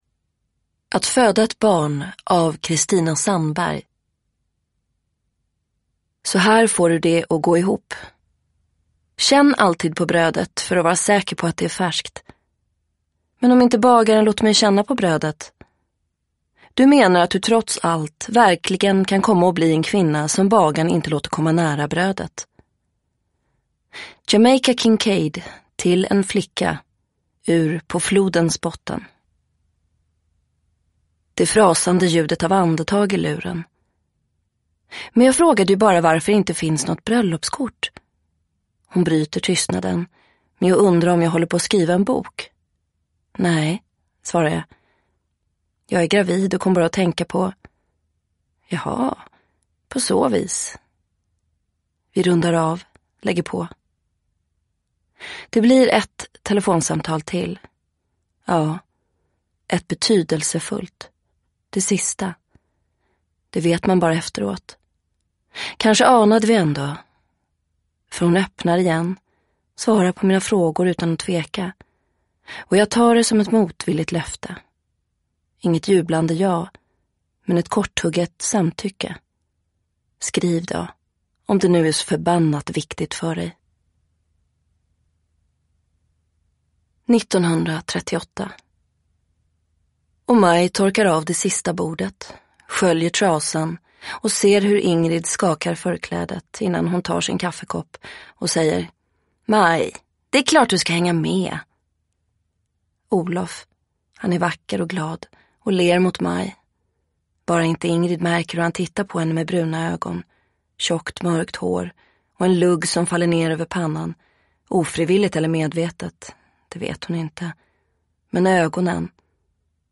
Att föda ett barn – Ljudbok – Laddas ner